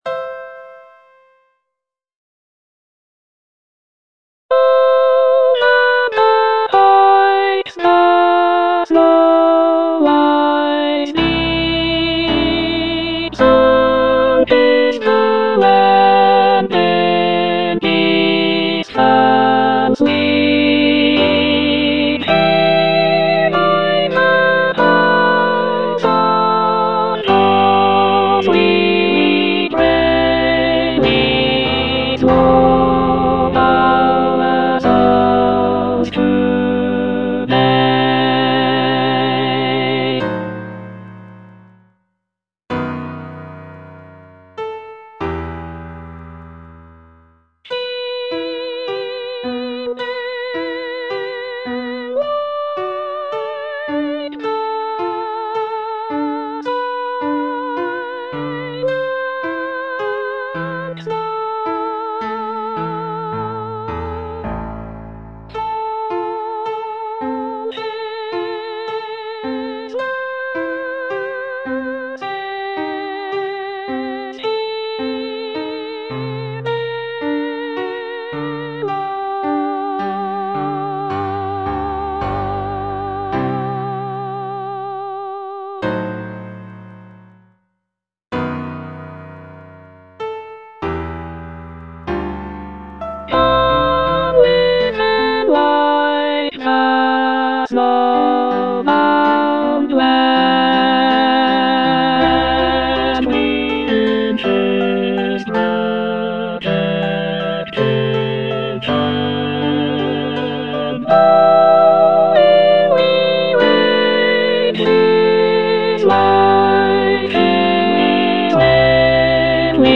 E. ELGAR - FROM THE BAVARIAN HIGHLANDS Aspiration (alto II) (Emphasised voice and other voices) Ads stop: auto-stop